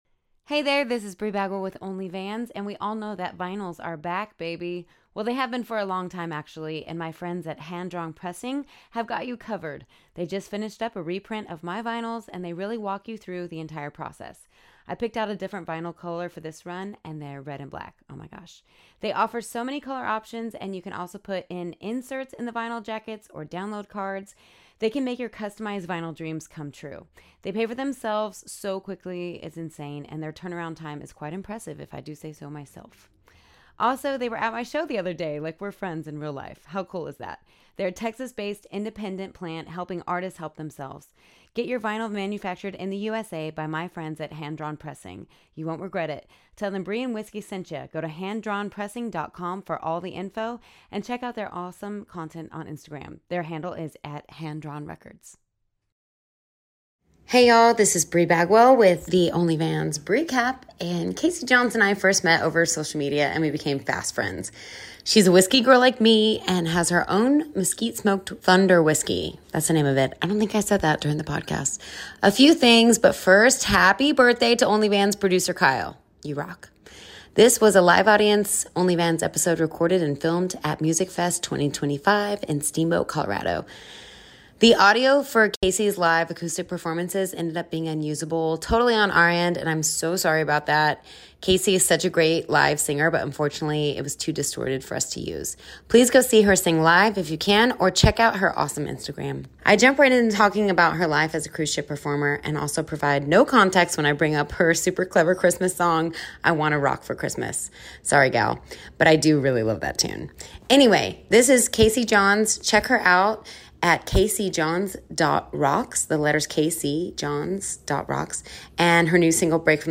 This was a live-audience episode recorded and filmed at MusicFest in Steamboat, Colorado. The audio for her live acoustic performances ended up being unusable, totally on our end, and I am so sorry about that.